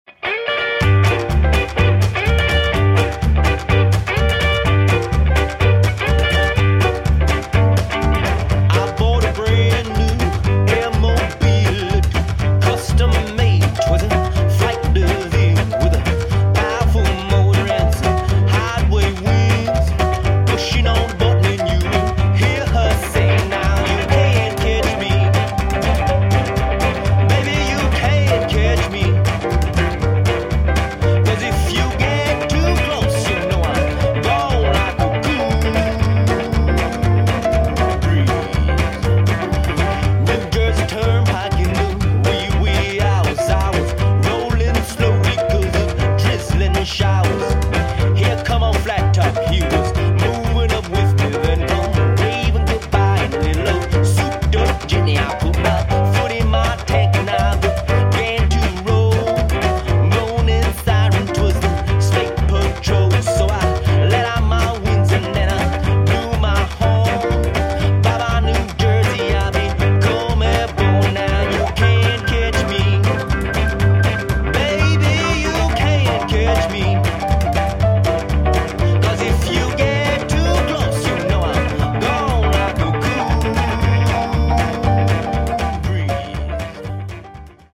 Blues Band
vintage dance tunes from the 1950’s